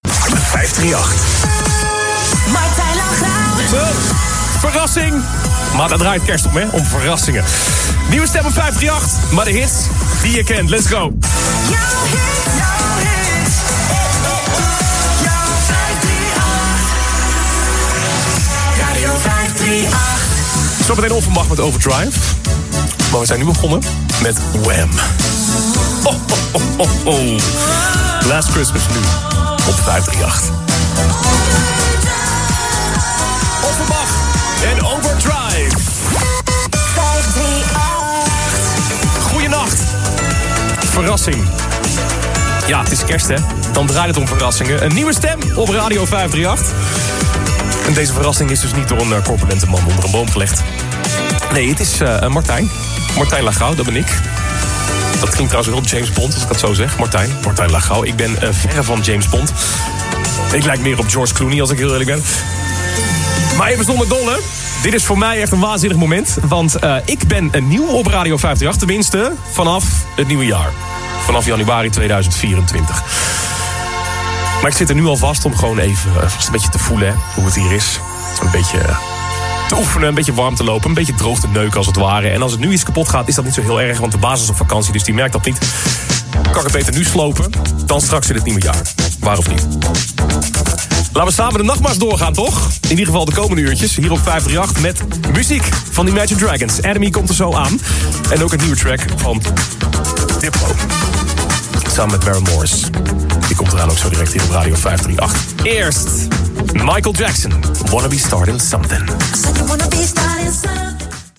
De deejay vierde de overgang van eerste naar tweede kerstdag met een proefprogramma bij zijn nieuwe werkgever.